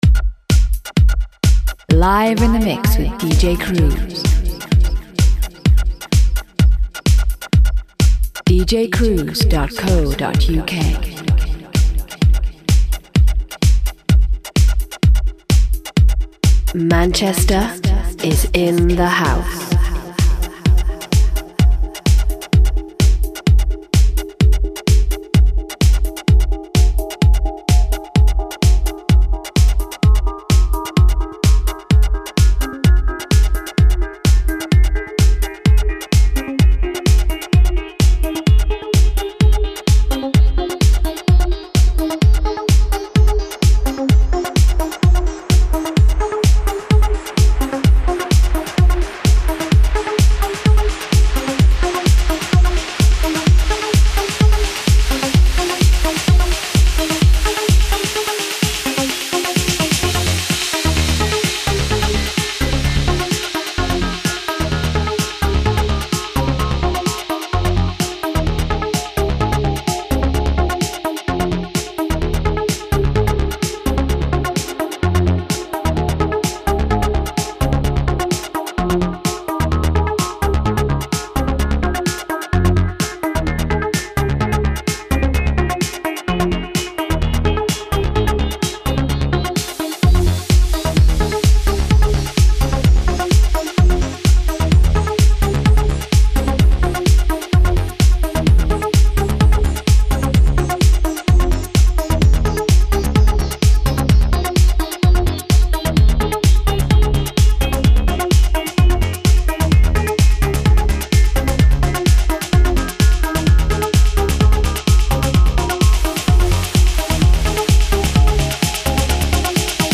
A funky house music mix